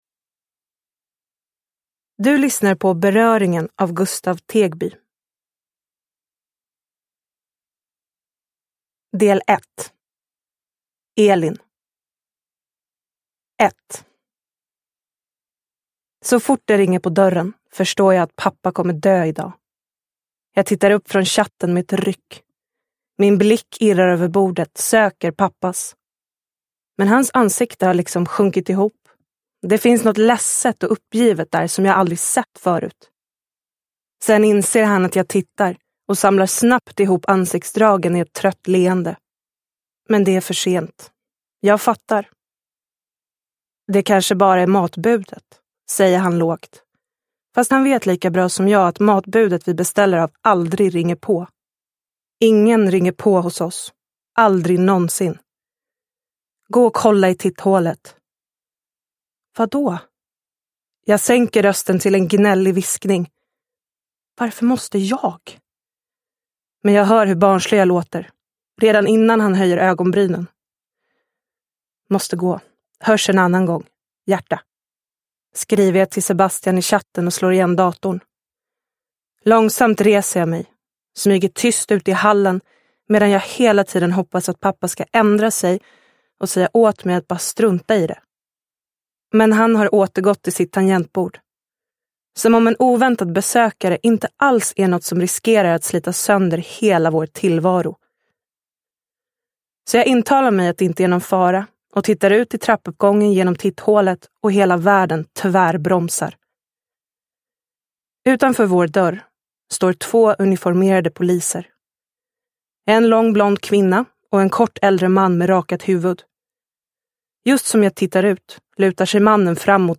Beröringen – Ljudbok – Laddas ner